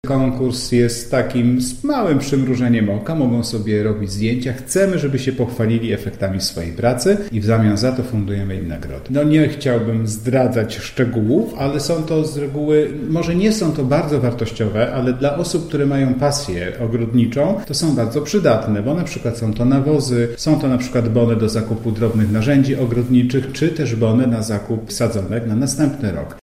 Mikołaj Tomaszyk, zastępca burmistrza Świebodzina, mówi, że jest to działanie, które ma poprawić wygląd miasta oraz zachęcić społeczeństwo do porządkowania swojego otoczenia: